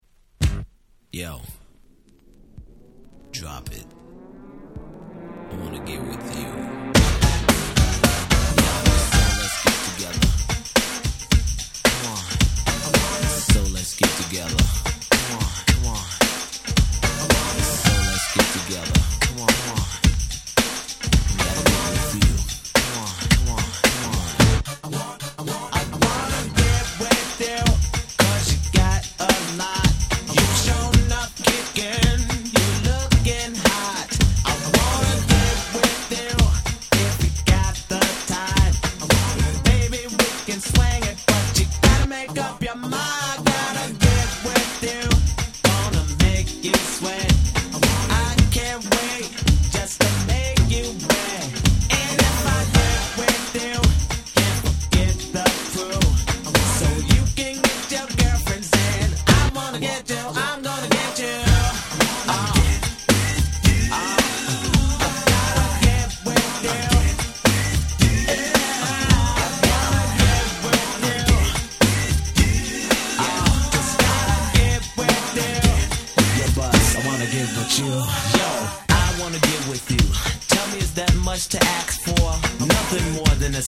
90' Big Hit New Jack Swing !!
ニュージャックスウィング